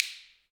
Index of /90_sSampleCDs/Roland - Rhythm Section/PRC_Clap & Snap/PRC_Snaps